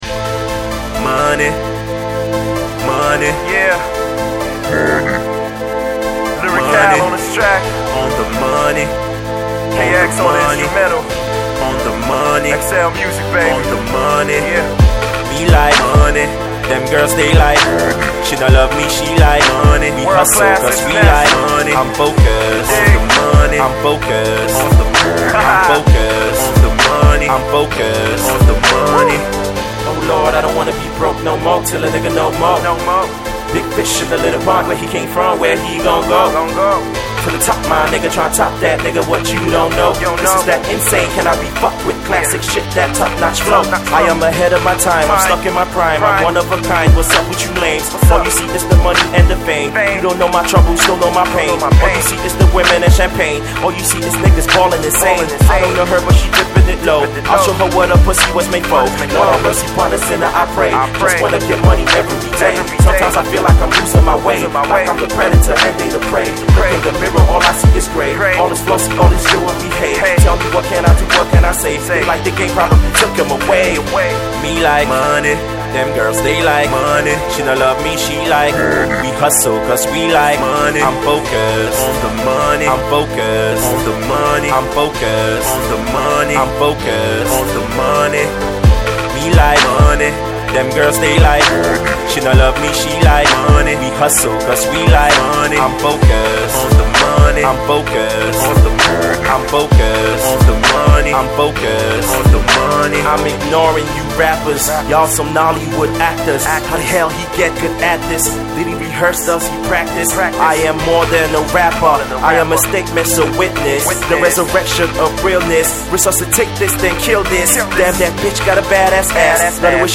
PH-based Rap cat
Hip-Hop cut